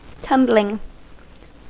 Ääntäminen
Ääntäminen US Tuntematon aksentti: IPA : /tʌmbliŋ/ Haettu sana löytyi näillä lähdekielillä: englanti Käännöksiä ei löytynyt valitulle kohdekielelle.